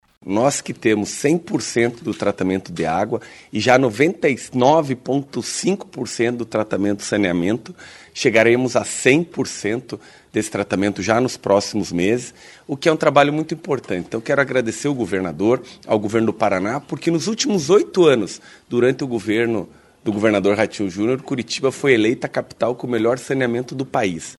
O prefeito de Curitiba, Eduardo Pimentel, afirmou que as obras promovem economia circular e sustentabilidade.